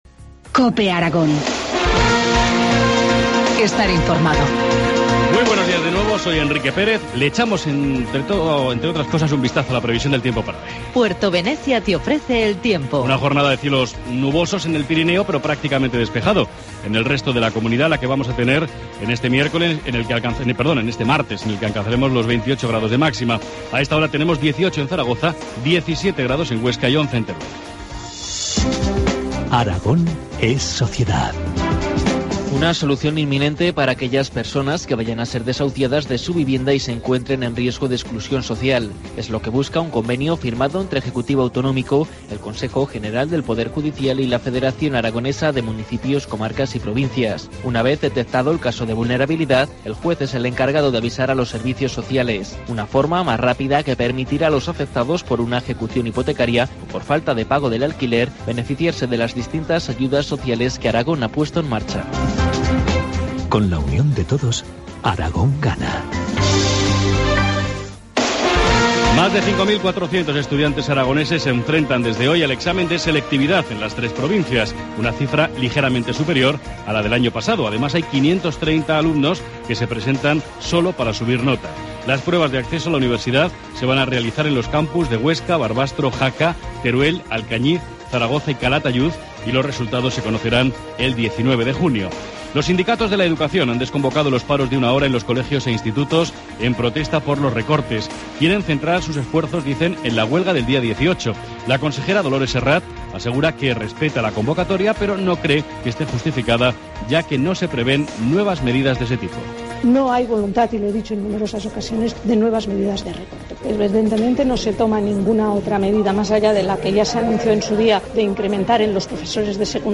Informativo matinal, martes 11 de junio, 8.25 horas